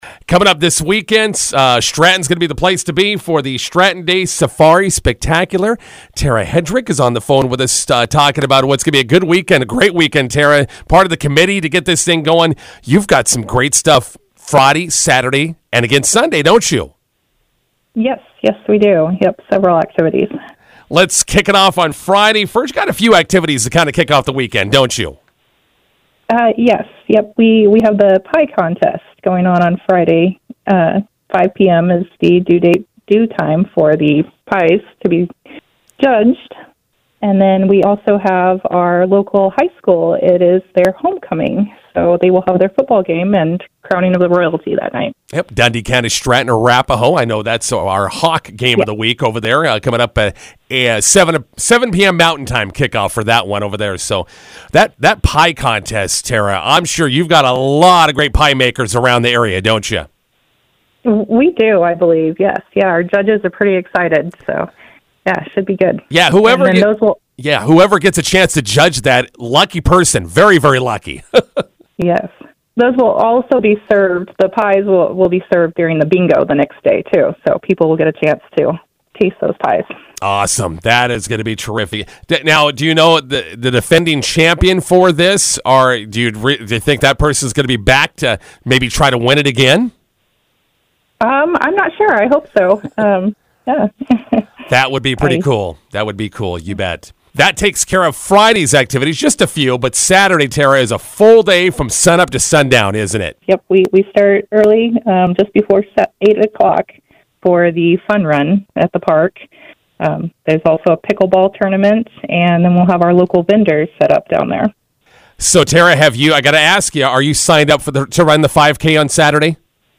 INTERVIEW: Stratton Days coming up this weekend.